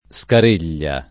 Scareglia [ S kar % l’l’a ] top. (Tic.)